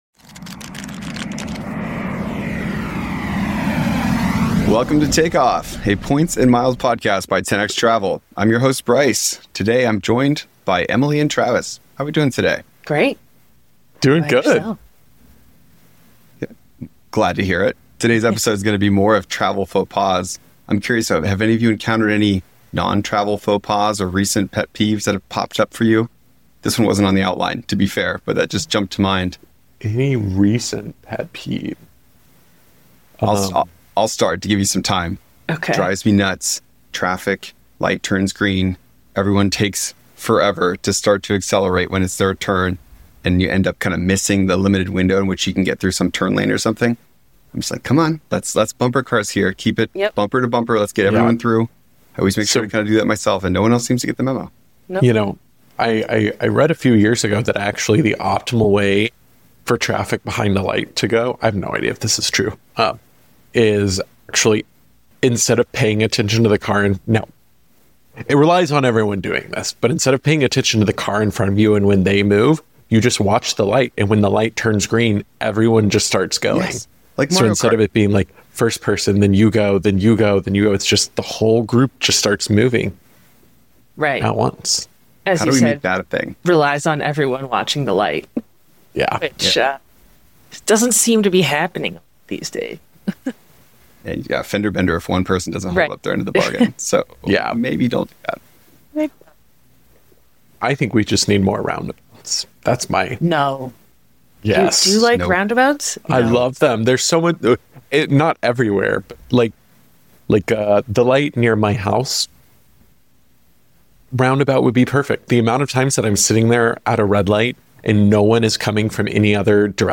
In this powerful intervention from Date with Destiny 2024, Tony Robb…